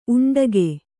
♪ uṇḍage